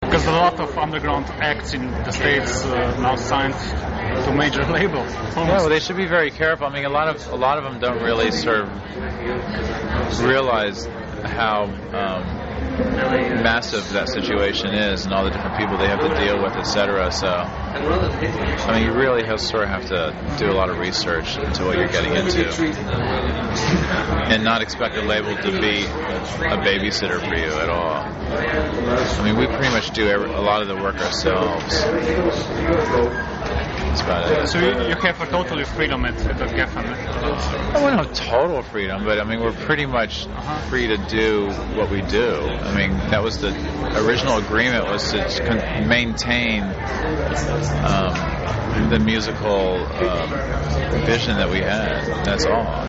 Intervju: SONIC YOUTH (Thurston Moore)